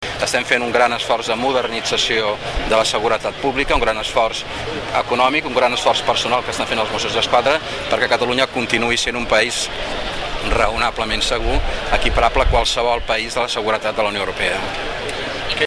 TALL DE VEU SAURA